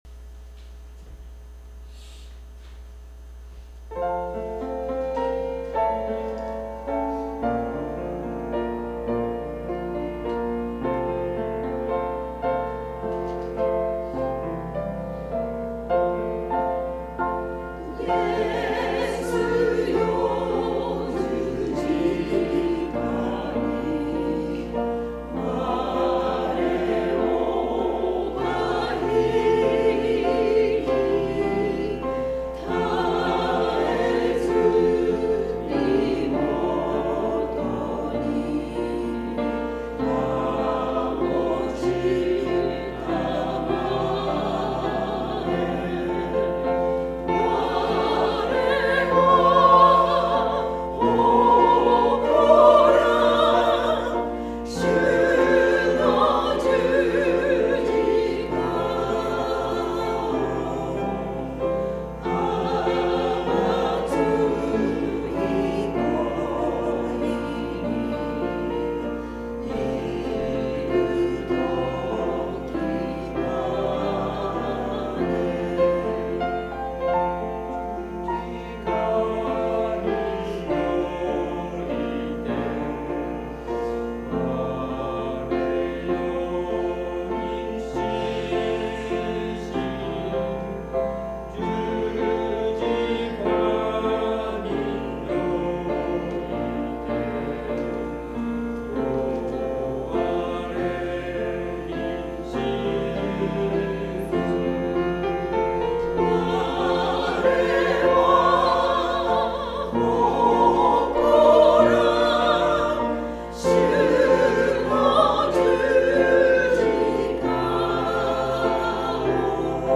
2026年3月8日聖歌隊賛美『イエスよ十字架に』音声ファイルです。